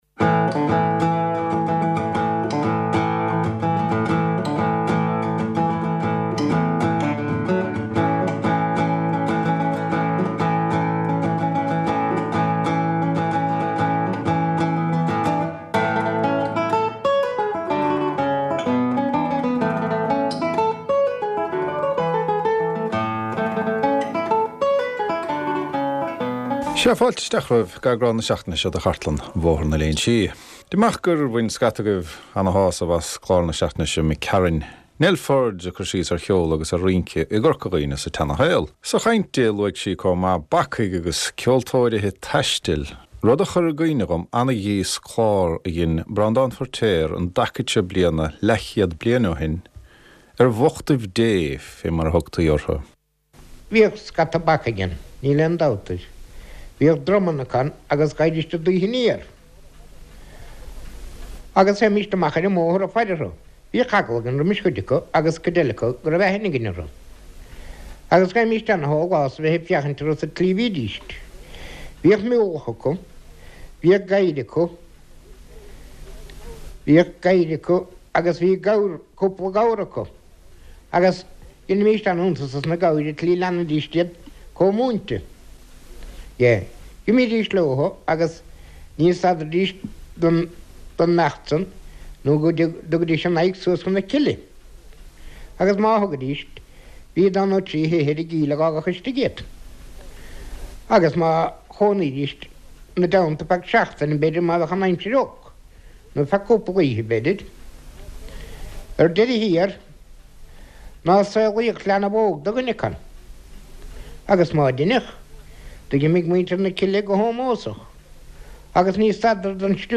Glórtha ó chartlann Bhóthar na Léinsí - scéalta agus seanchas, amhráin agus ceol, stair agus sochaí na Gaeltachta. / Voices from RTÉ Raidió na Gaeltachta's Baile na nGall archive, including stories and folklore, songs and music, history and descriptions of the Gaeltacht community.